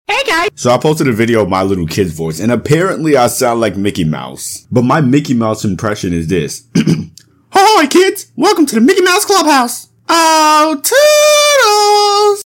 Like if i sound like Mickey mouse